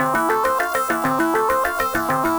Index of /musicradar/8-bit-bonanza-samples/FM Arp Loops
CS_FMArp A_100-C.wav